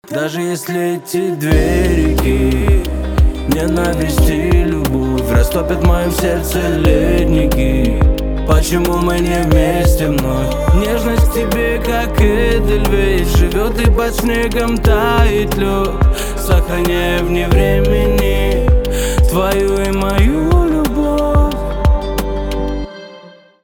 поп
грустные , романтические , гитара